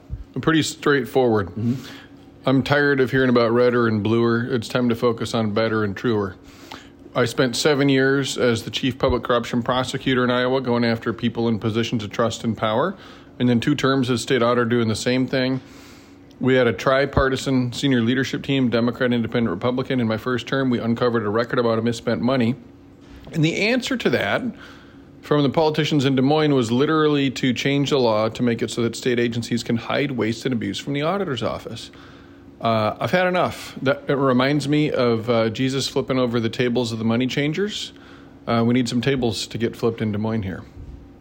A Conversation with KILJ